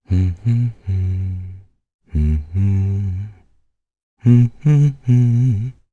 Crow-Vox_Hum_jp_b.wav